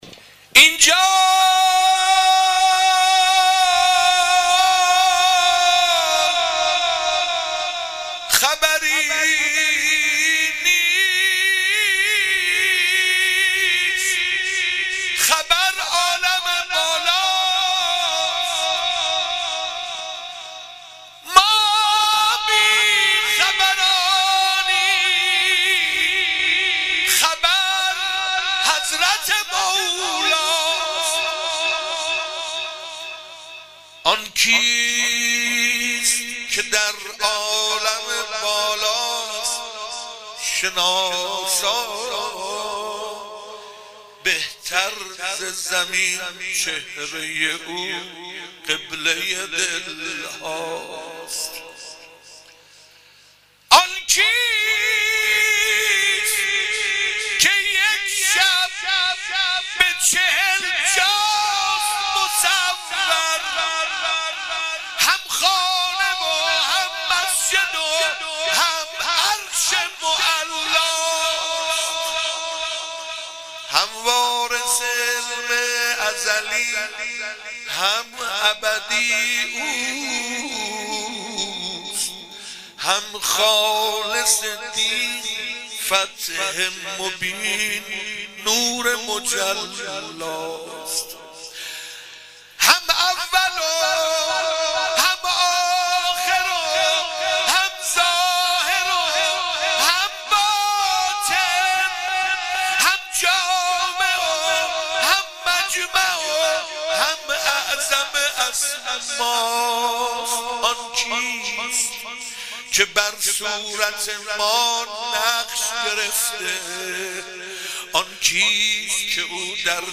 مدح - اینجا خبری نیست
عید غدیر